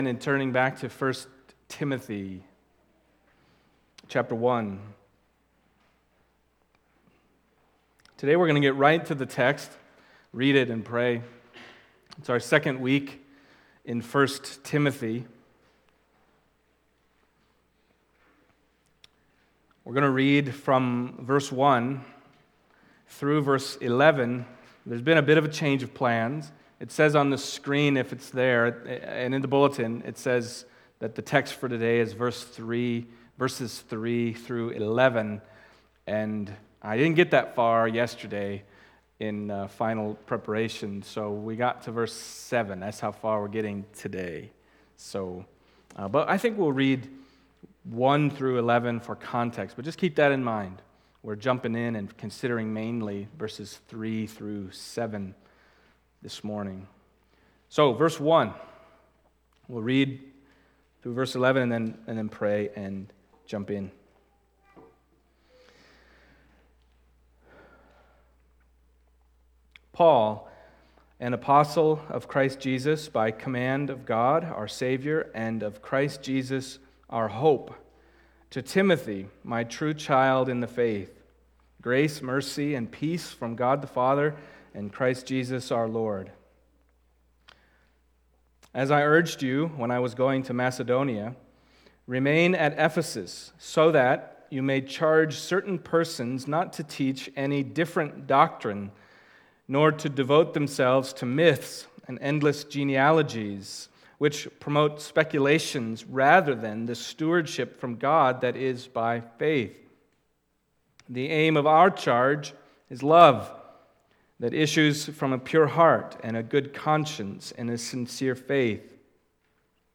Passage: 1 Timothy 1:3-7 Service Type: Sunday Morning